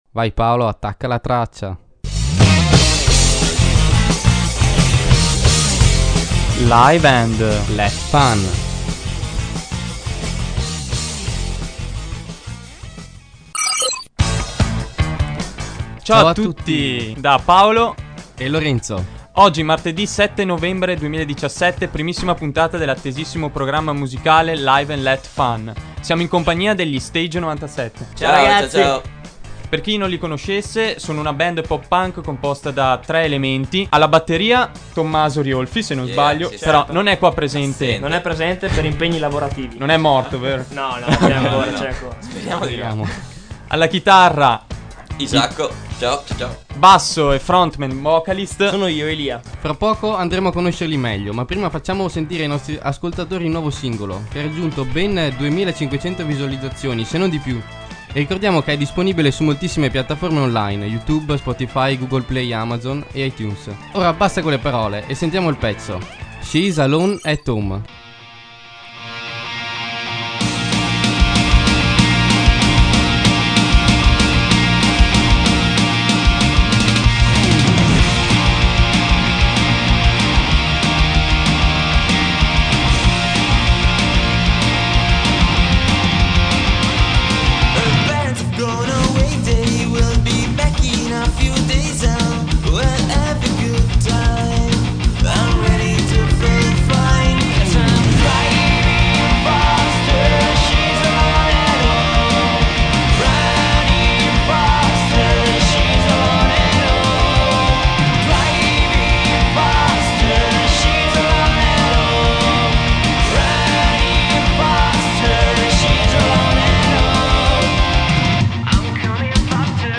Il programma si occupa di musica Live e di Band Underground, con interviste e soprattutto tanta musica.